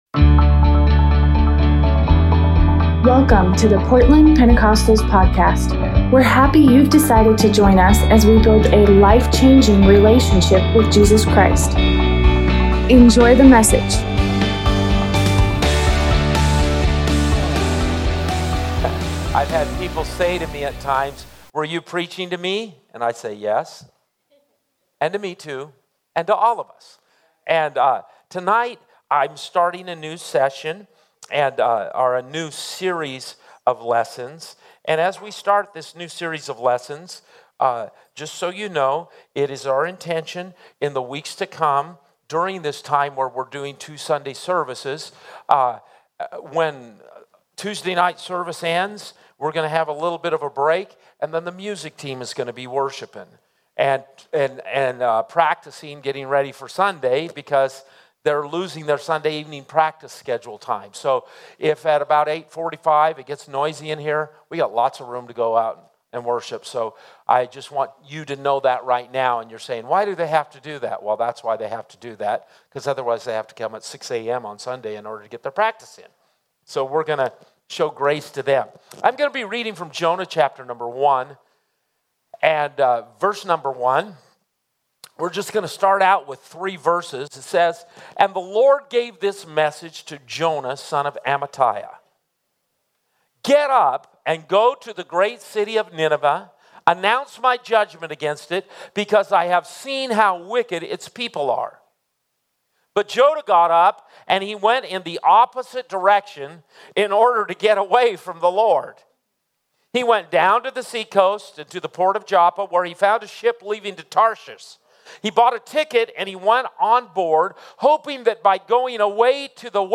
Tuesday Night Bible Study By